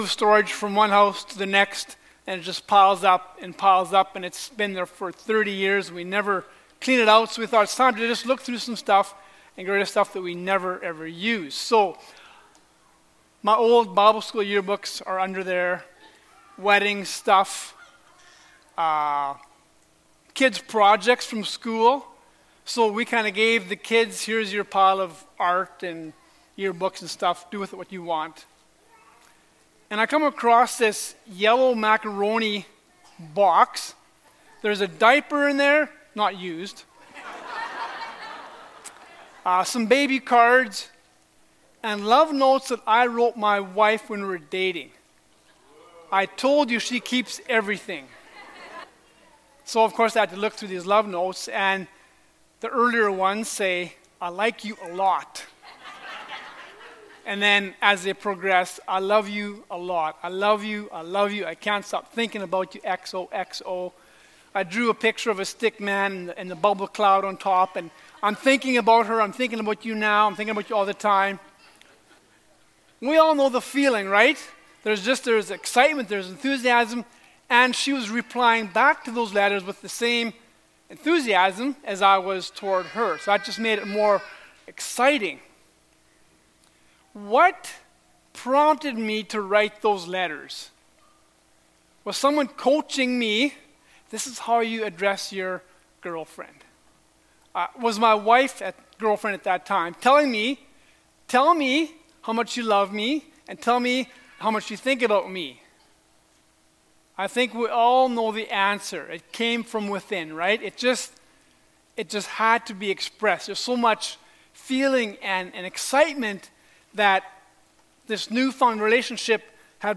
Passage: Luke 1:46-56 Service Type: Sunday Morning Topics